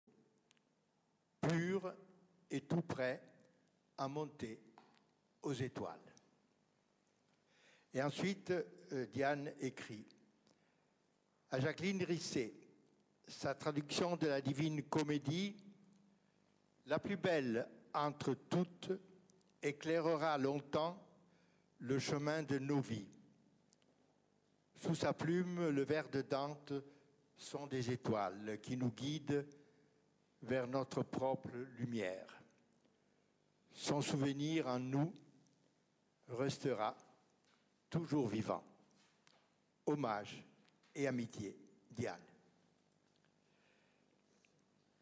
Paris, Collège des Bernardins.